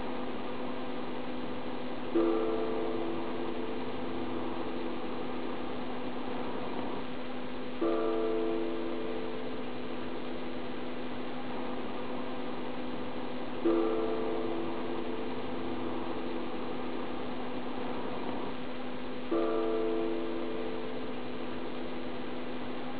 そんなわがふるさと沼南町にも朝6時になると近所のお寺から梵鐘が鳴るのである。
「夜明けの鐘の音」は沼南町・長国山妙照寺
「夜明けの鐘の音」
〜鐘を叩くと、梵鐘が聞こえます〜
毎日6時00分から05分までの5分間、13回鐘を突くという。